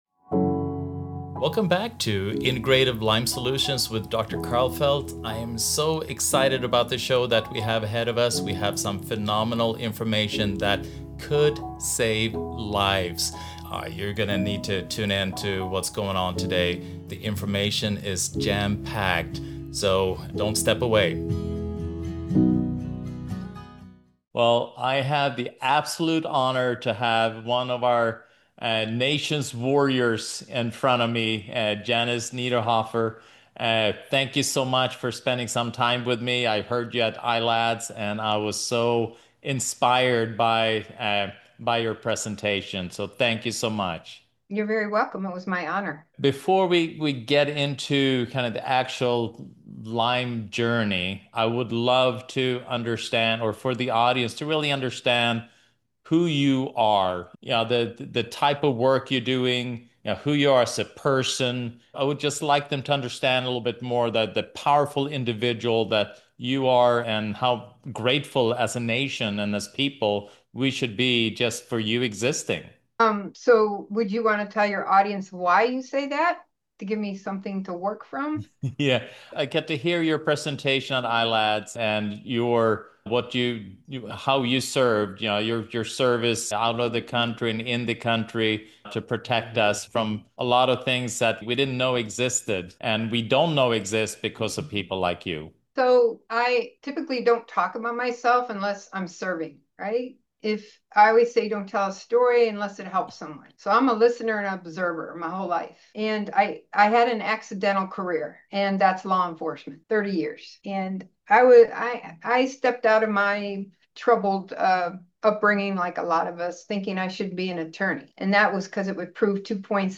She emphasizes the importance of finding expert care, being compliant with treatments, and maintaining a positive outlook. This inspiring conversation sheds light on the challenges faced by Lyme disease sufferers and offers hope through persistence and support.